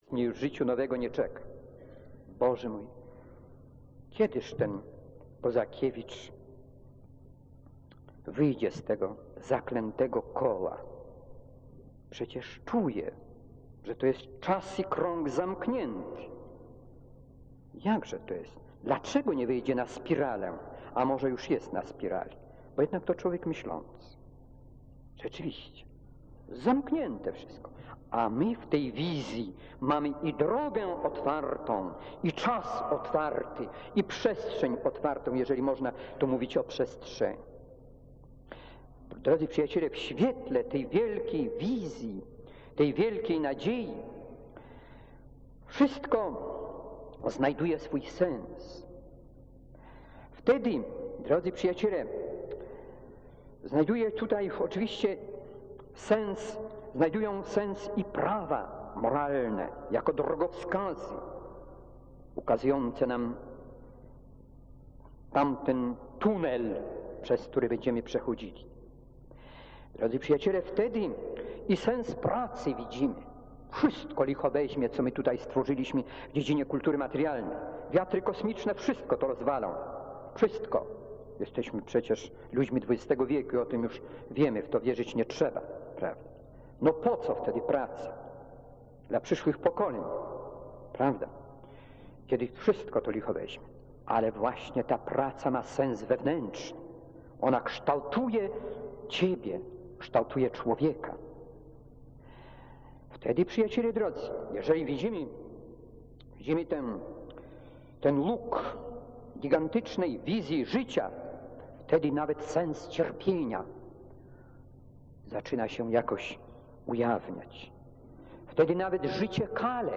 Rekolekcje
rekolekcje1979_Sumienie_Milosc.mp3